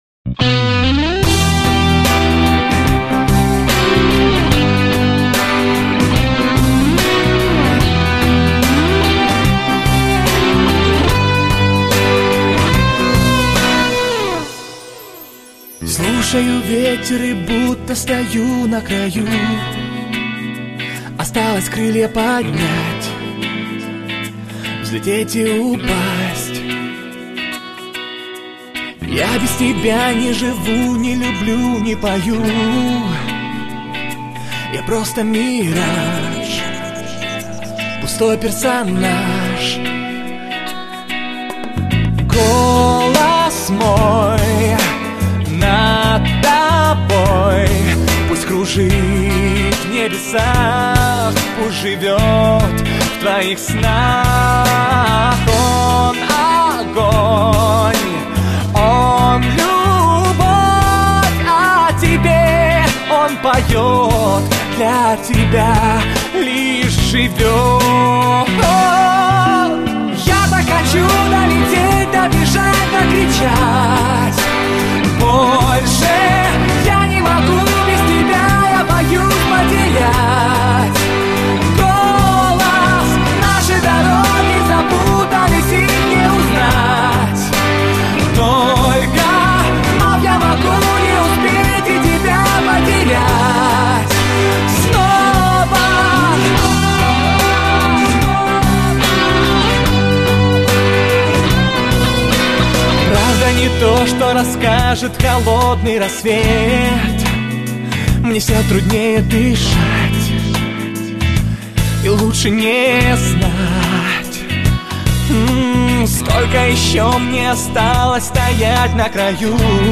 Забыла добавить, что эту песню мужчина поёт...